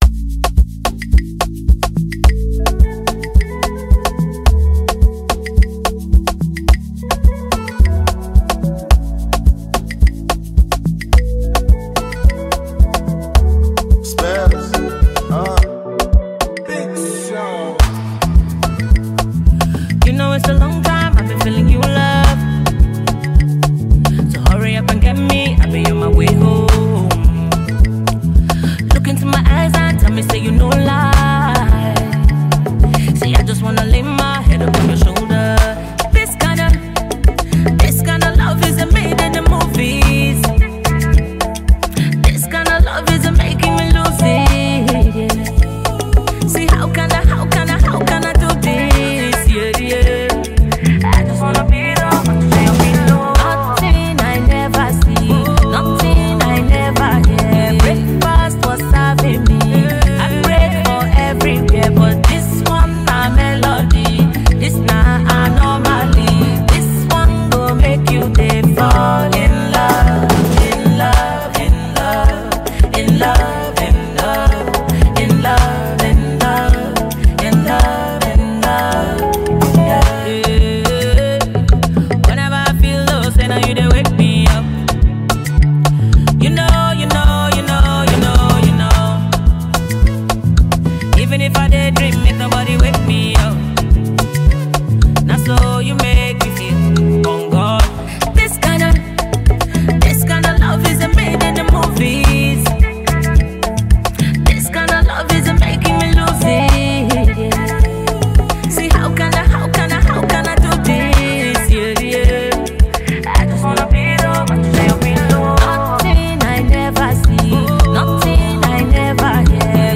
feel-good anthem that deserves a spot on your playlist.